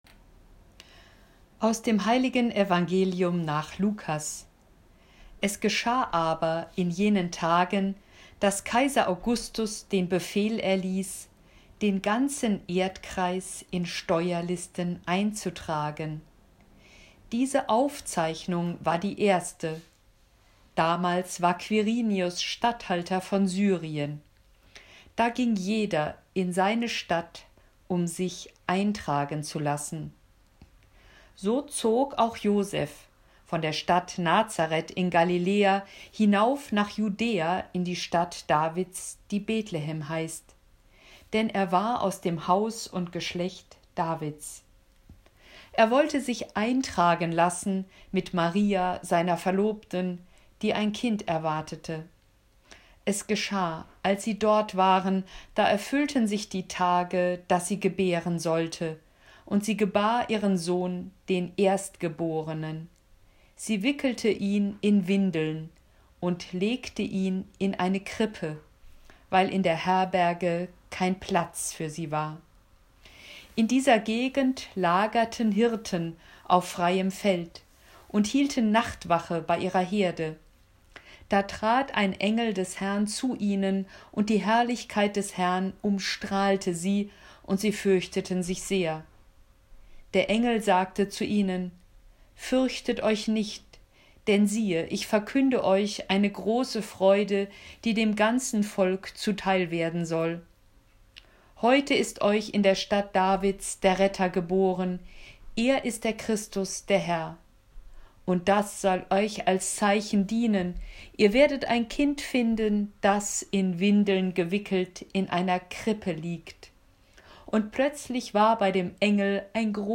Sprechtraining
Audio-Datei zum Üben der Aussprache von liturgischen Texten